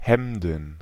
Ääntäminen
Ääntäminen Tuntematon aksentti: IPA: /ˈhɛmdn̩/ Haettu sana löytyi näillä lähdekielillä: saksa Käännöksiä ei löytynyt valitulle kohdekielelle. Hemden on sanan Hemd monikko.